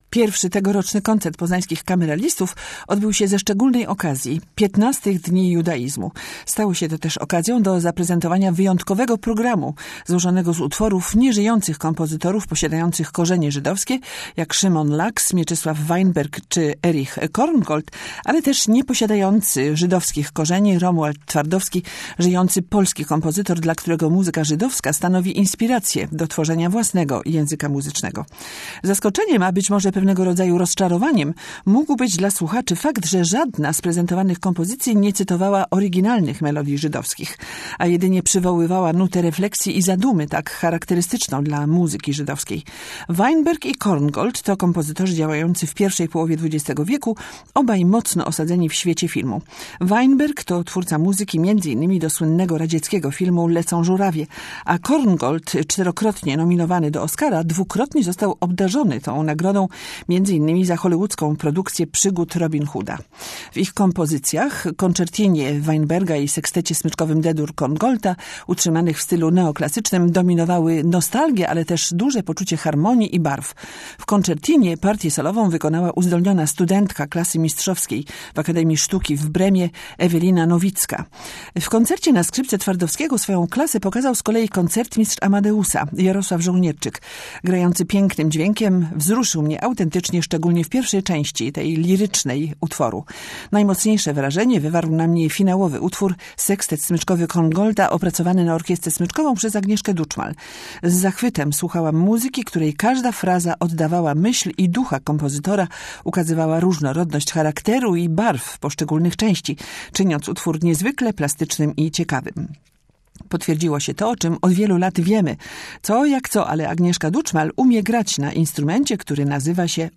Orkiestra Kameralna „Amadeus” Polskiego Radia pod dyrekcja Agnieszki Duczmal wystąpiła na koncercie w ramach XV Dnia Judaizmu.